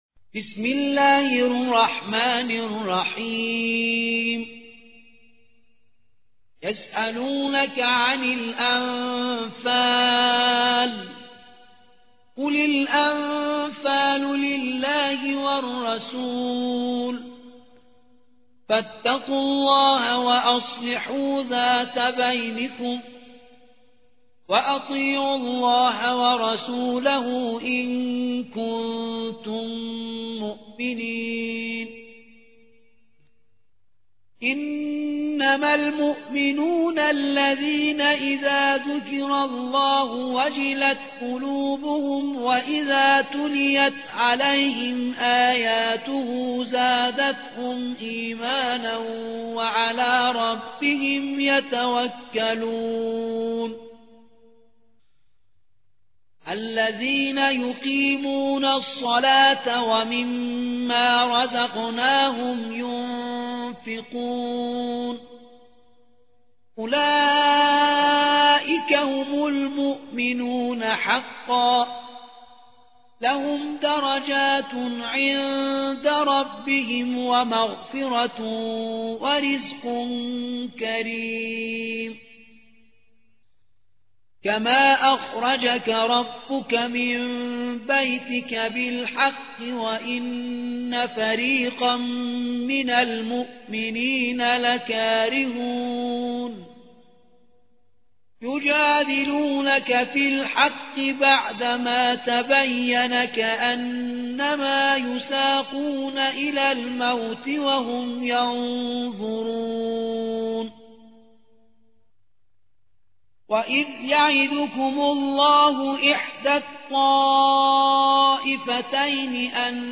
القرآن الكريم